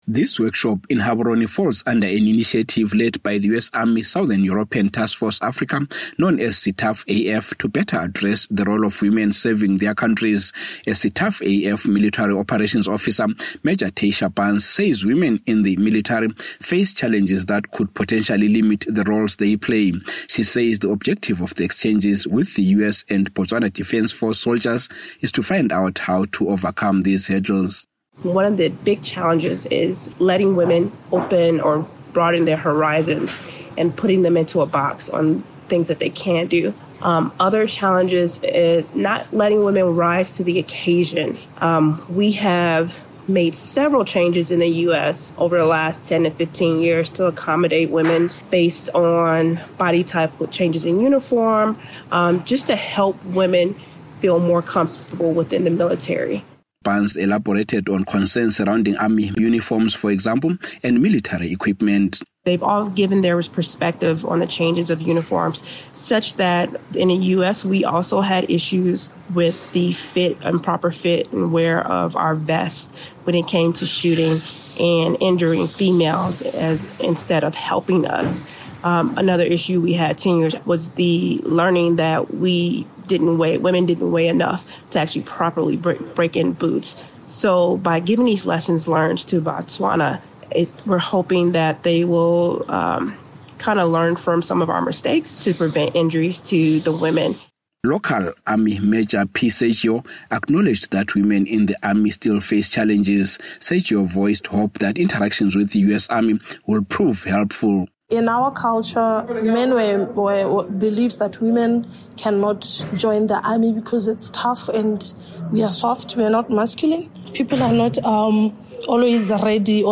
Military personnel from the United States and Botswana Defense Force are wrapping up workshops aimed at strengthening relations as well as building the capacity of local forces. One three-day workshop is focusing on better integrating women into Botswana’s army, addressing issues like sexual harassment and the need for tailored equipment. From Gaborone